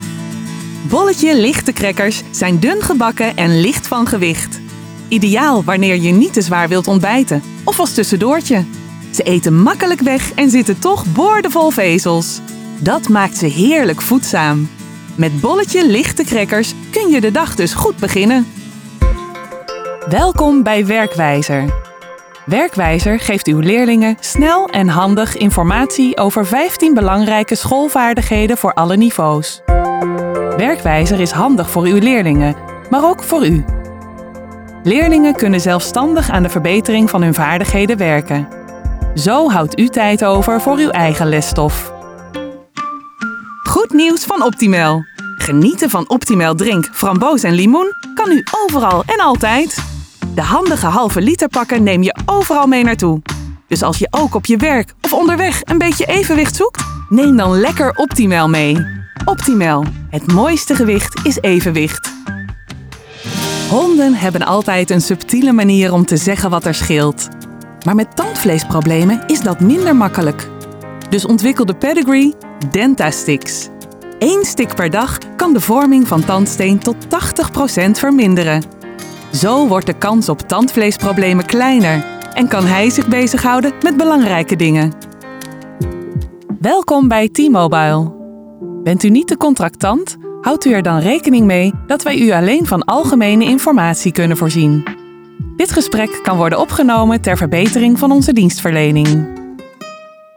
Ik spreek graag je bedrijfsfilms, commercials, e-learning en voice-reponssysteem in. Fris, enthousiast, vriendelijk en zakelijk, wat jij wilt.